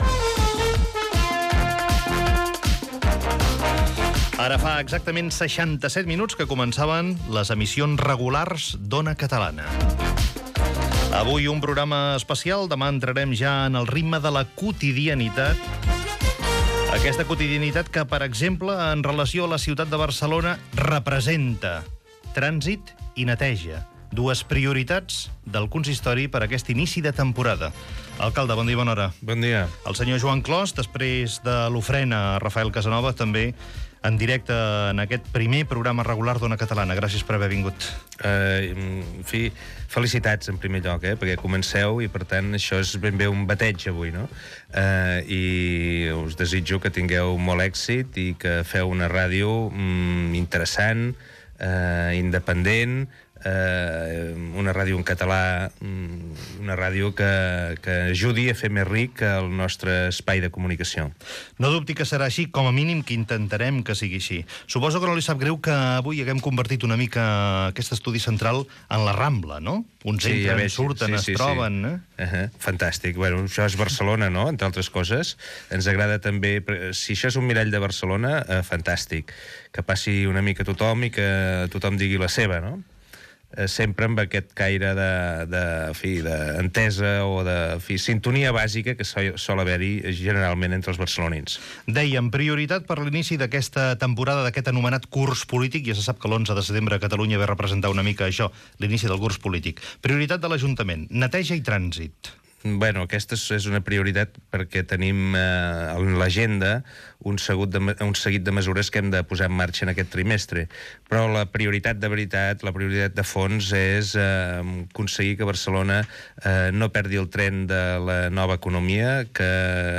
Fragment d'una entrevista a l'alcalde de Barcelona, Joan Clos i a l'exalcalde Pasqual Maragall.
Info-entreteniment